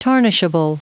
Prononciation du mot tarnishable en anglais (fichier audio)
Prononciation du mot : tarnishable